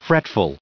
added pronounciation and merriam webster audio
1545_fretful.ogg